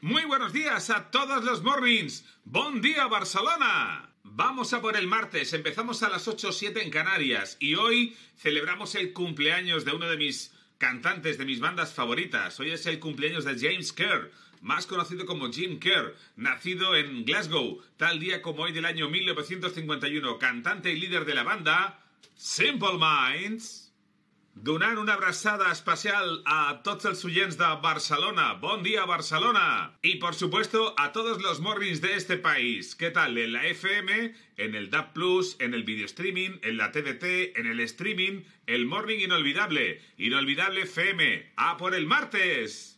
Salutació a l'audiència de Barcelona en el dia de l'anniversari de Jim Kerr de Simple Minds
Entreteniment